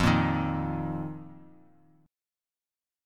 F7sus4 chord